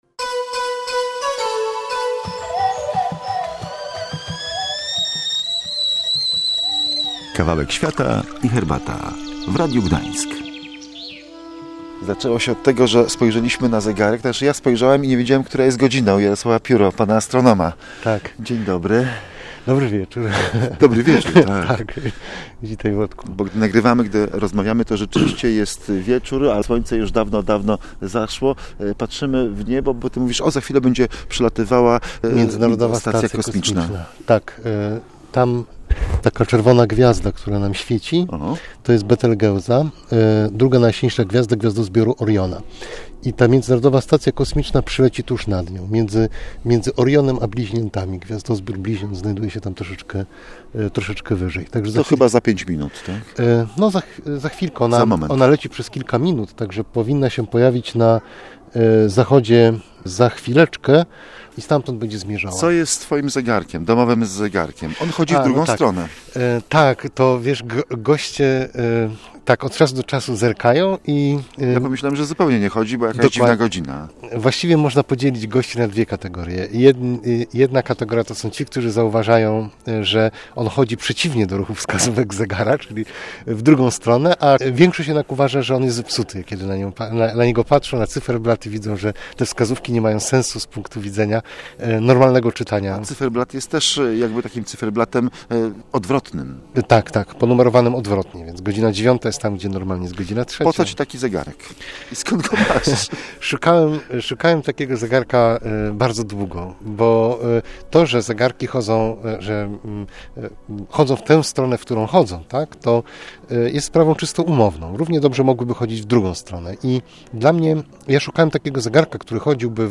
To było pretekstem do wielowątkowej radiowej rozmowy na ten temat.